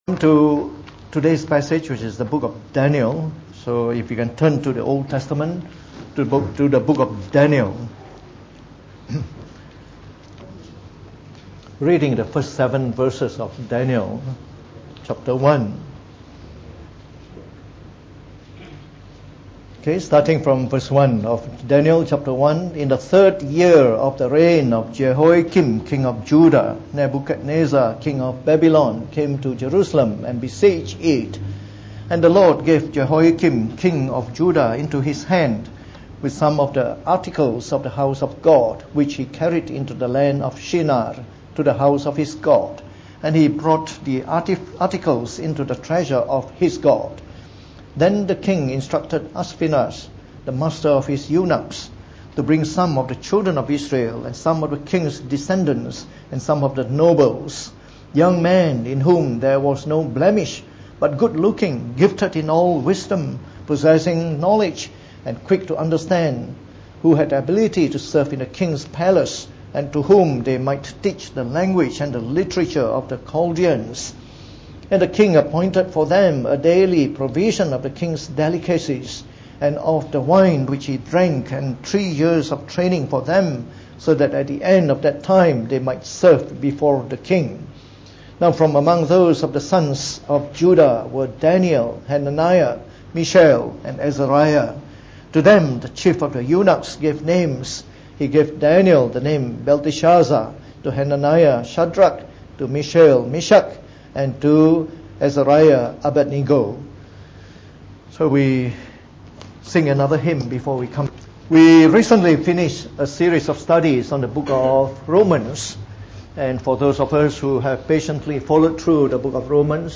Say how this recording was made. Preached on the 18th of November 2018.